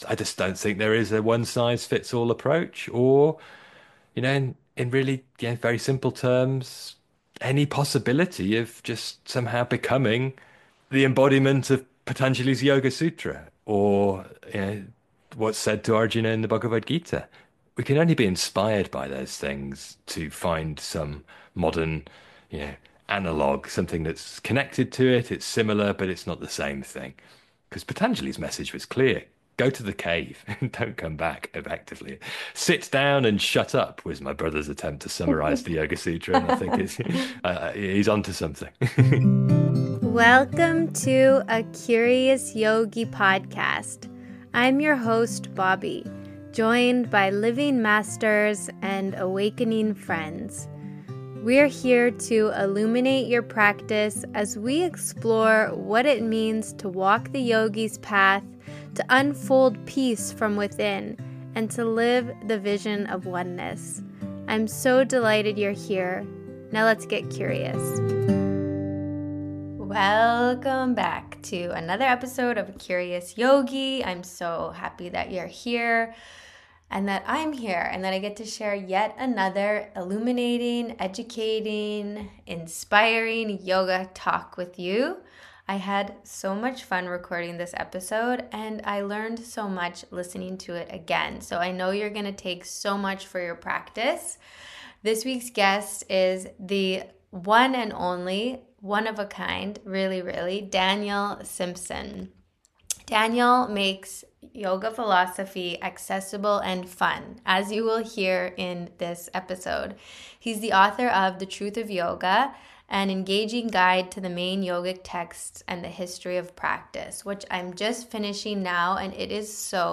Join me weekly for joyful conversations with wise teachers, sincere yogis and other spiritual seekers. We inquire into our deepest questions and learn how to apply the ancient wisdom into our own daily practice…and life.